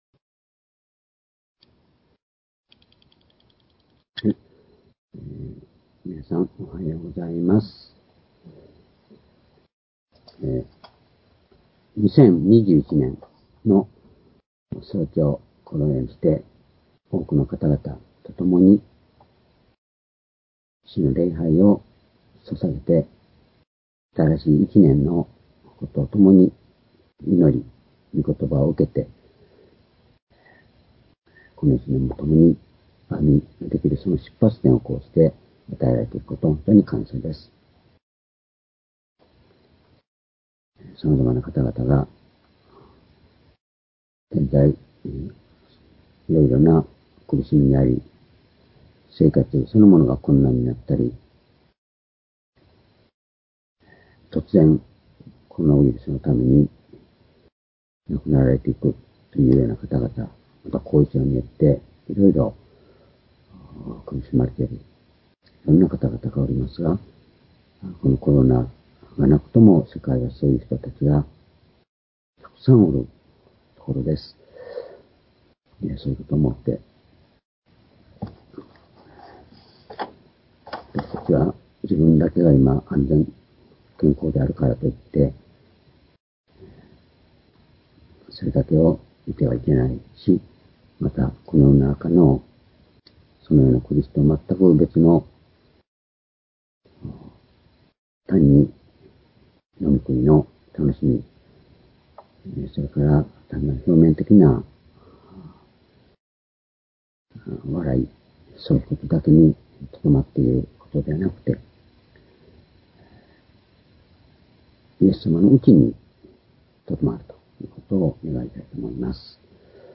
主日礼拝日時 2021年１月１日（元旦礼拝） 聖書講話箇所 「我が内にとどまれ」 ヨハネ福音書15章4節、7節 ※視聴できない場合は をクリックしてください。